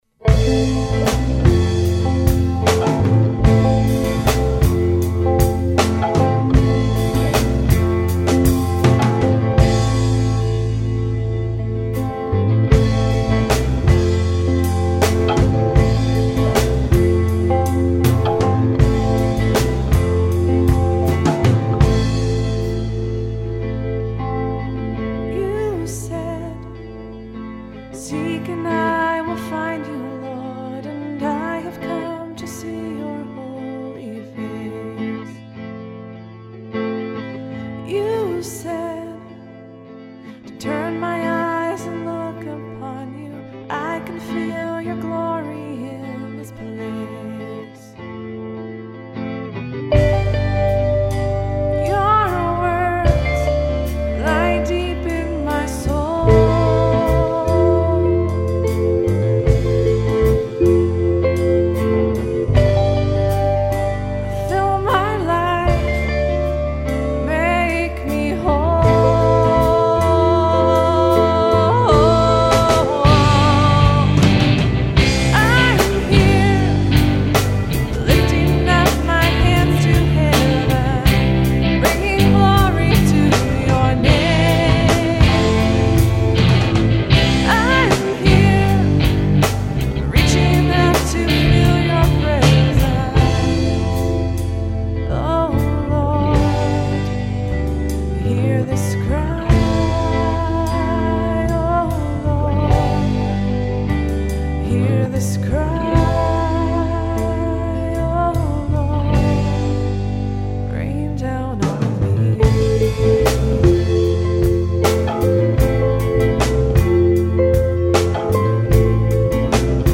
Vocals / Acoustic Guitar
Bass
Electric Guitar
Drums